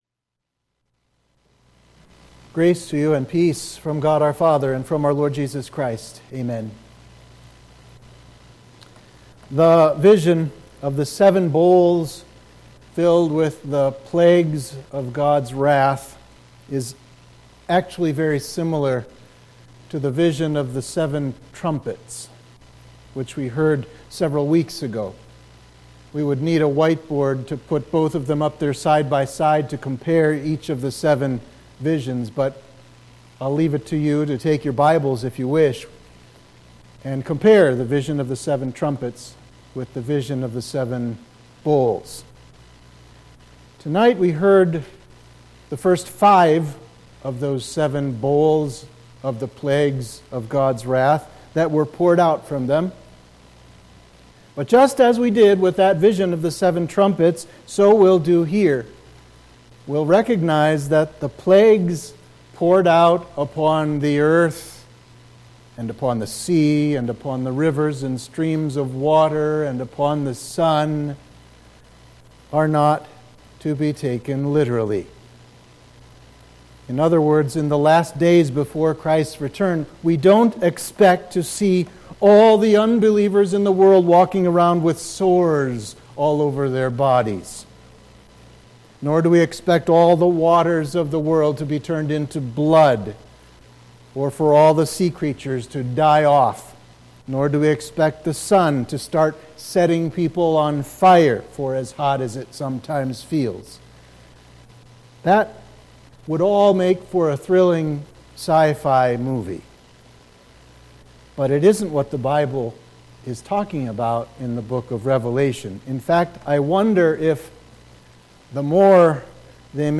Sermon for Midweek of Trinity 11